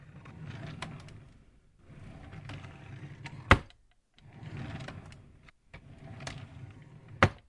抽屉开启和关闭
描述：木制抽屉滑动打开和关闭。